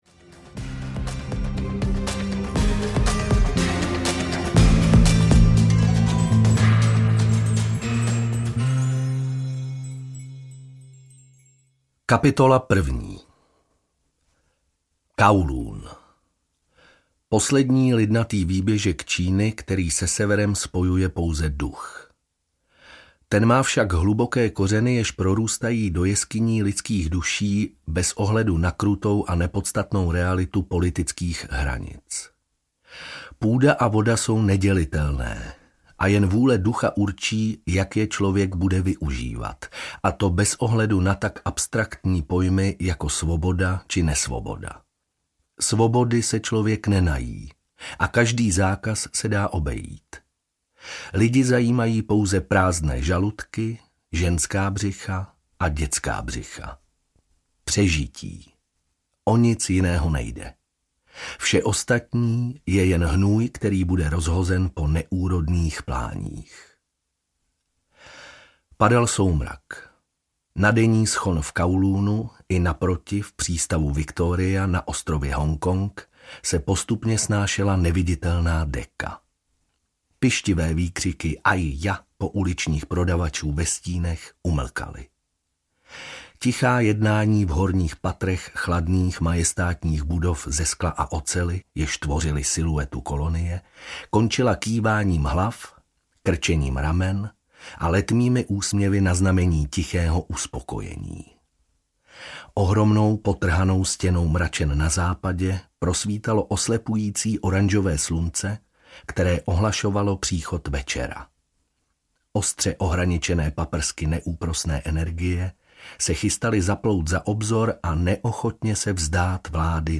Bourneův mýtus audiokniha
Ukázka z knihy